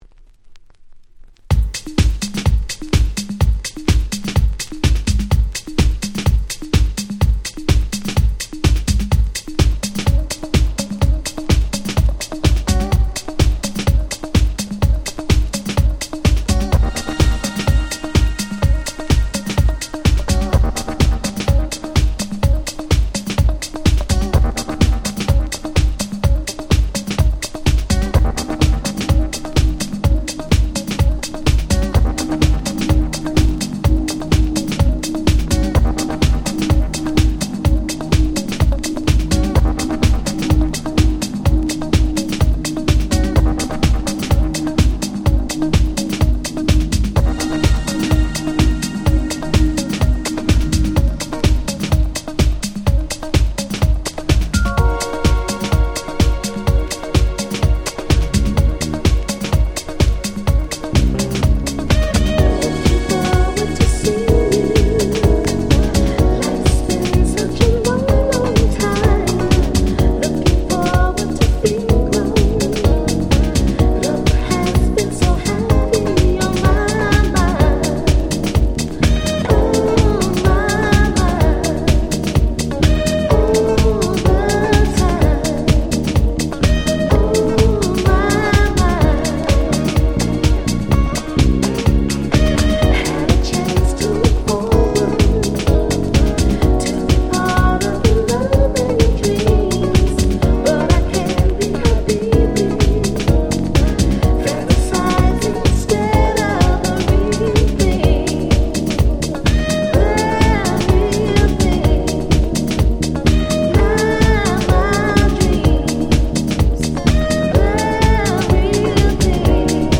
99' Super Nice Cover Vocal House !!